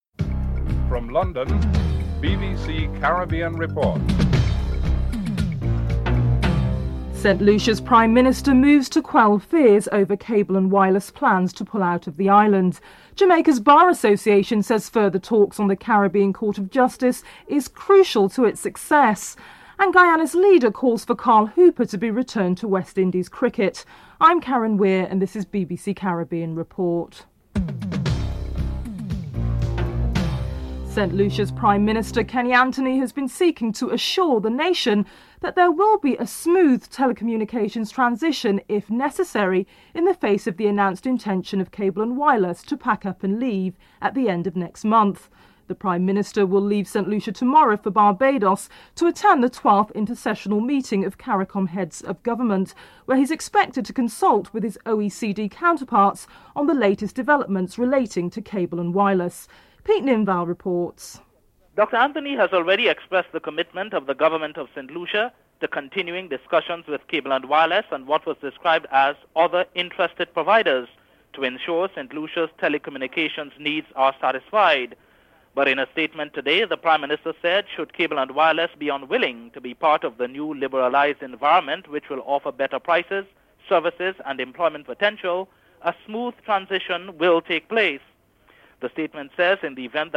1. Headlines (00:00-00:27)
6. Guyana's leader Bharath Jagdeo calls for Carl Hooper to be returned to West Indies cricket. Bharath Jagdeo is interviewed